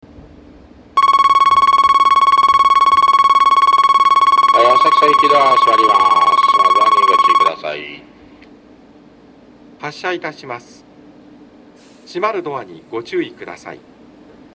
B線ホームで収録すると、真上にA線の線路があるのでよくガタンゴトンと被ります・・。
到着放送